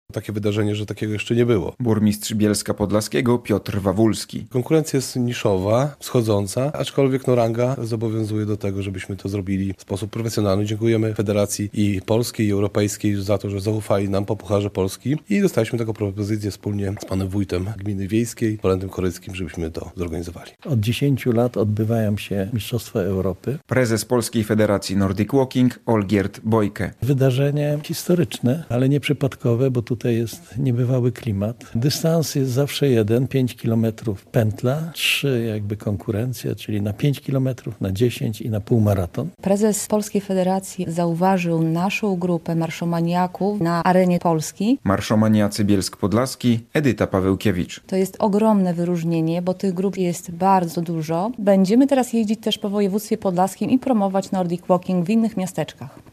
Mistrzostwa Europy w nordic walking - relacja